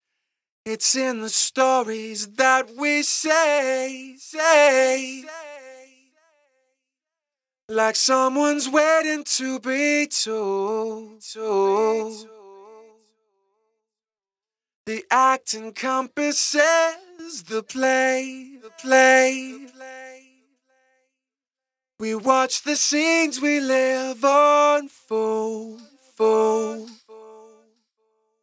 In the FX Bus, place a delay and two compressors, both sidechained to the vox track -
Quick n dirty audio file attached below.